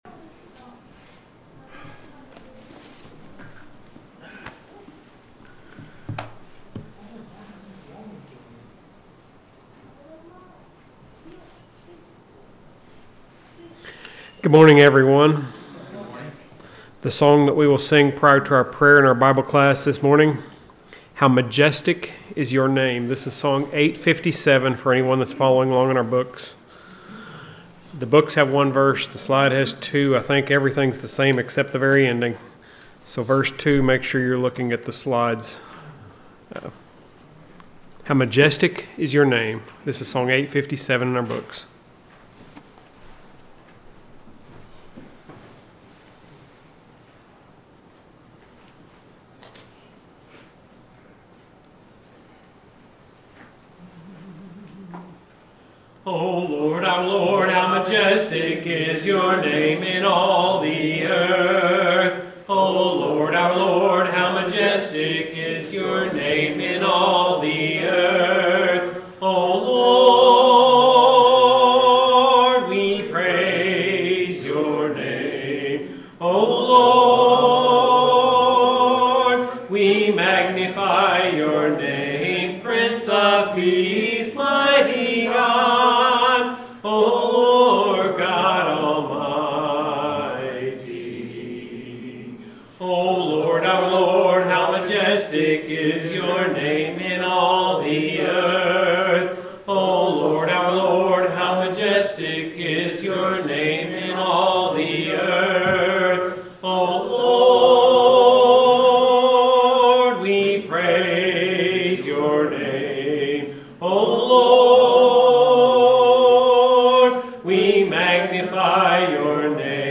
SUNDAY AM WORSHIP
The sermon is from our live stream on 12/7/2025